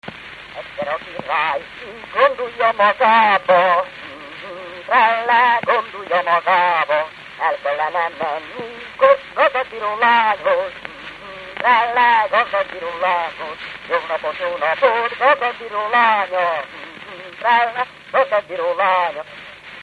Dunántúl - Tolna vm. - Sárpilis
Stílus: 8. Újszerű kisambitusú dallamok
Szótagszám: 6.6.6.6
Kadencia: X (X) X 1